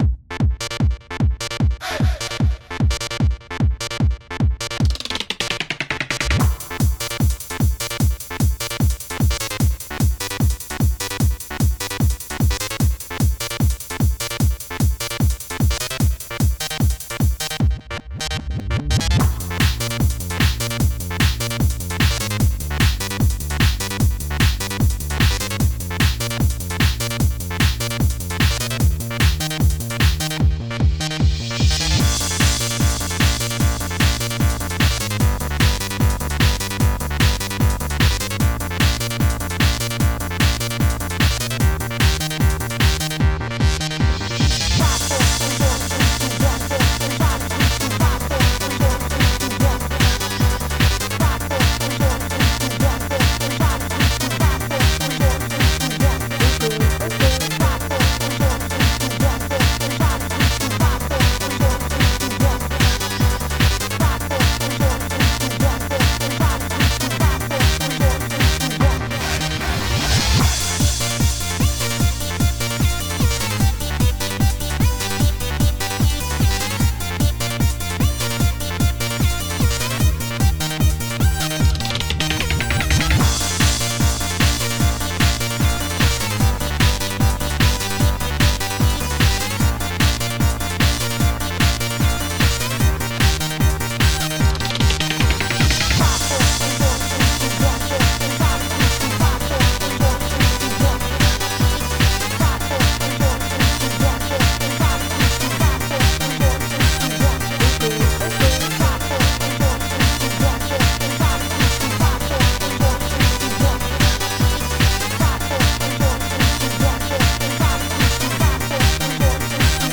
Hype Techno